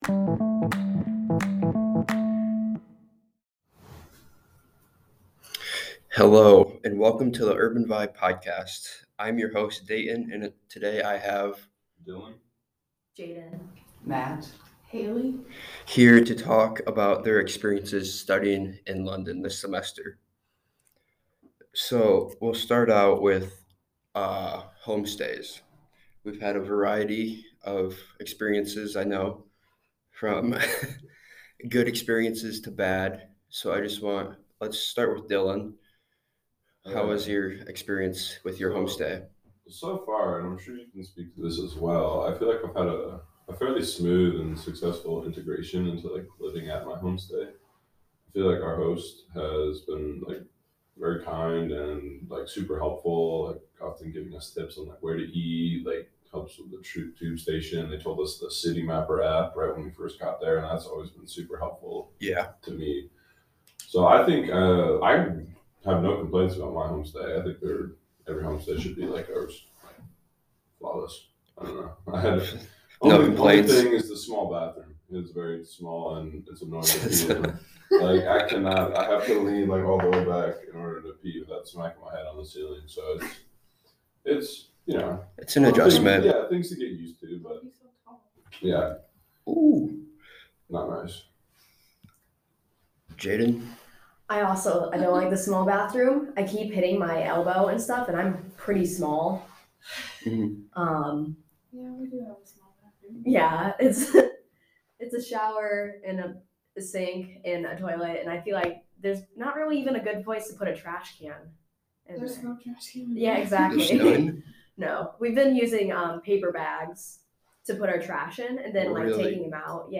Urban Vibe Podcast: Study Abroad Roundtable